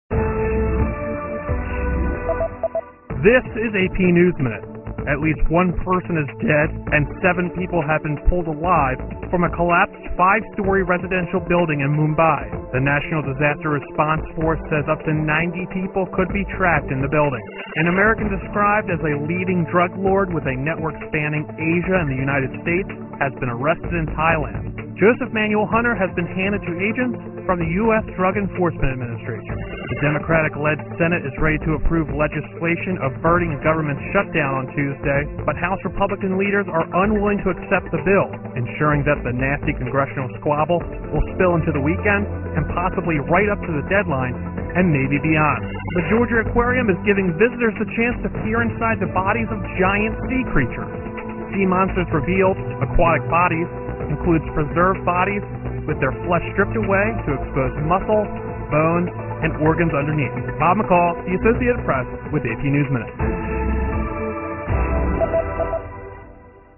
在线英语听力室美联社新闻一分钟 AP 2013-10-07的听力文件下载,美联社新闻一分钟2013,英语听力,英语新闻,英语MP3 由美联社编辑的一分钟国际电视新闻，报道每天发生的重大国际事件。